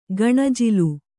♪ gaṇijile